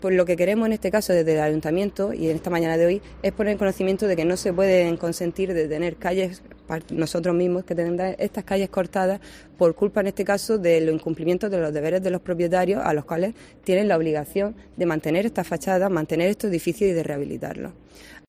María Hernández, concejal del PP de Lorca